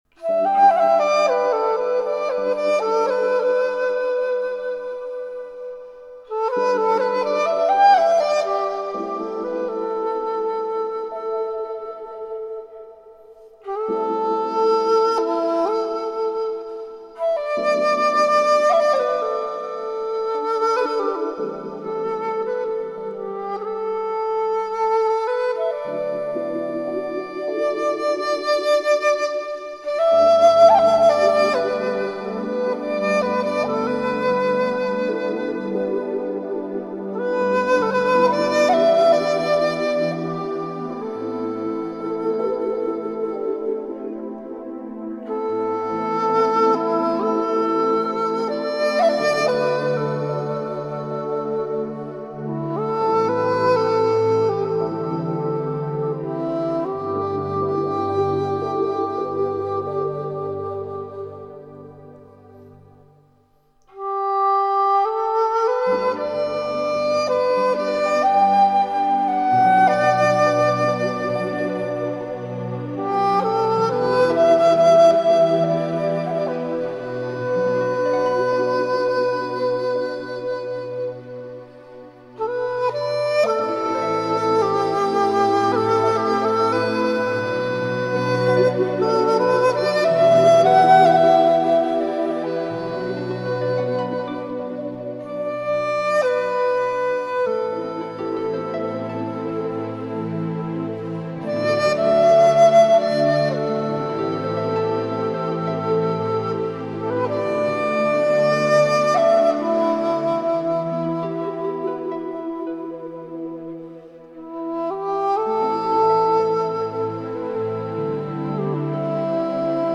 箫、笛、钢琴、大提琴等乐器与大自然的虫鸟叫相互应和，